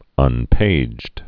(ŭn-pājd)